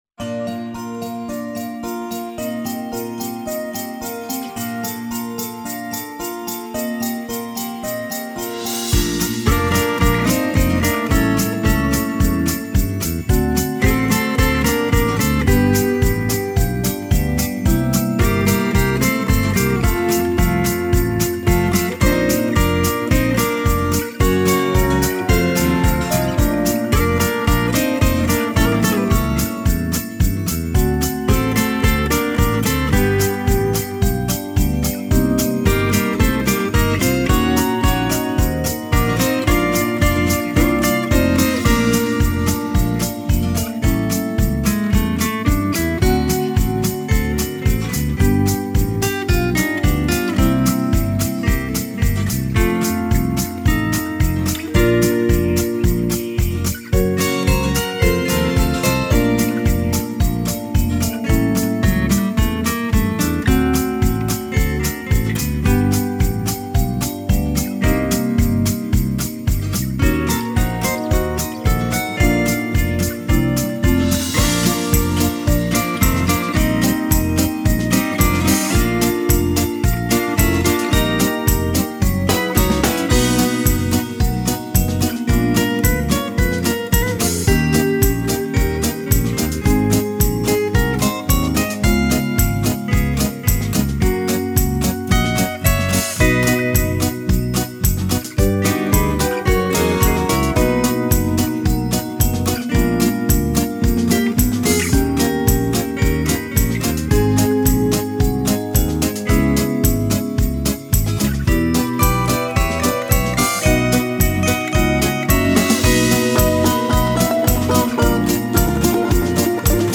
минус песни